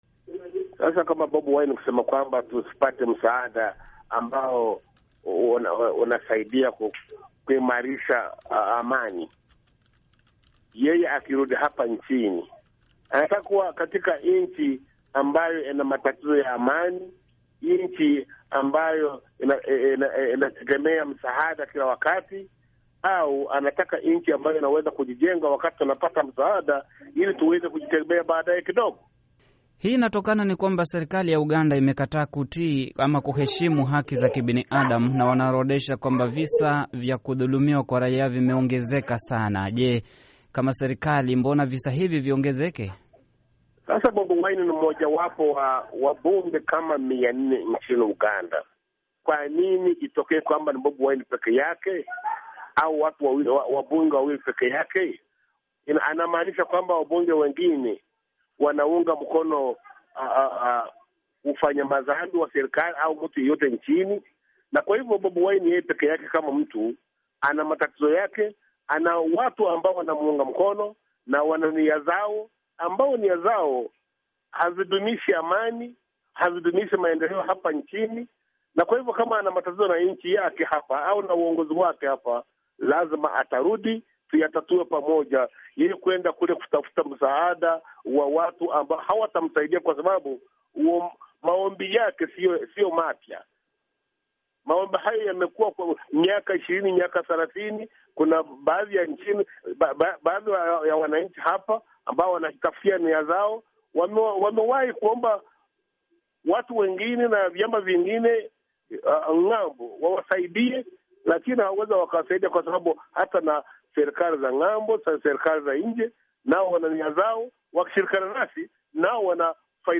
Mahojiano na msemaji wa serikali ya Uganda